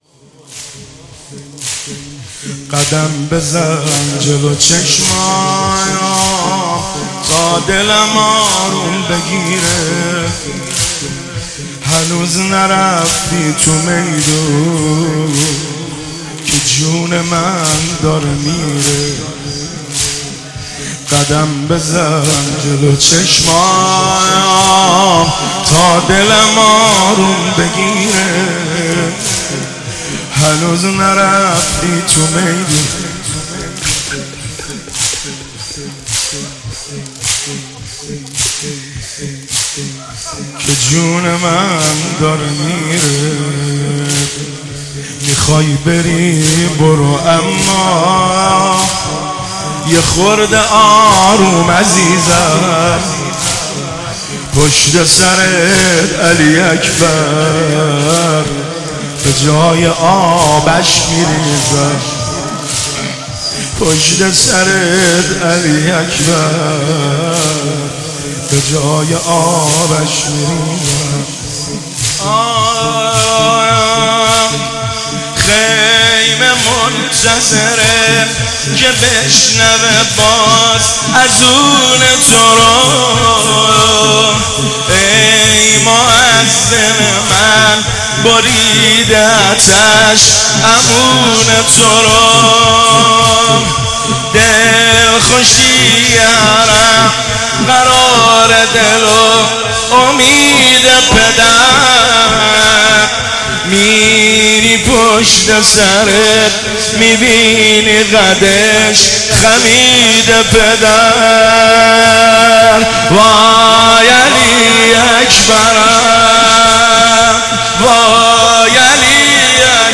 گلچین مداحی شب هشتم ماه محرم 1403 | ضیاءالصالحین
.به مناسبت فرا رسیدن ماه محرم، گلچین مداحی های دلنشین شب هشتم محرم از مادحین مبارک نفس را تقدیم محبان و عاشقان حضرت علی اکبر علیه السلام می نماییم